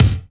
SWING BD 9.wav